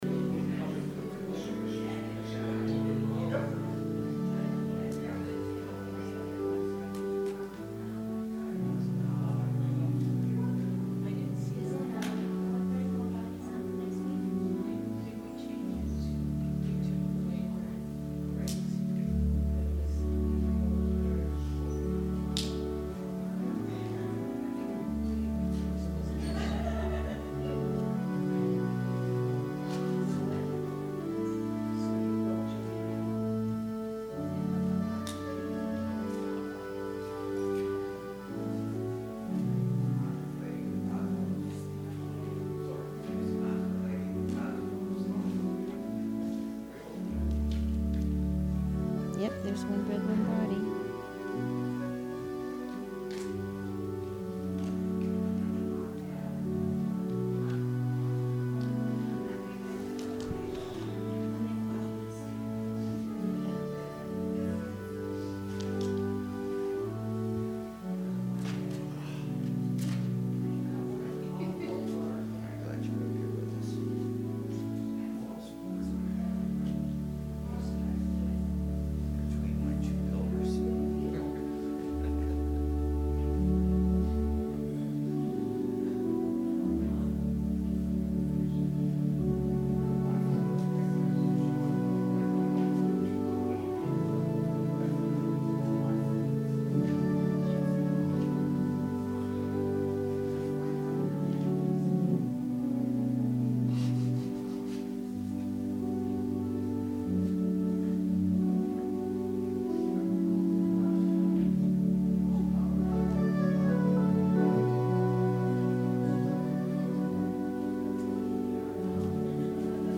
Sermon – November 18, 2018